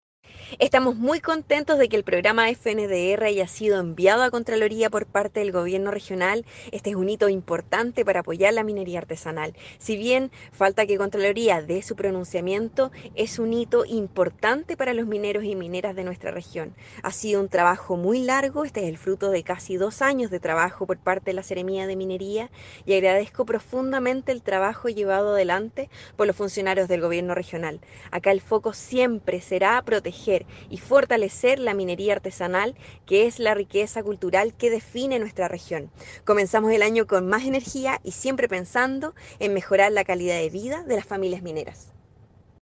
La seremi de Minería, Constanza Espinoza indicó que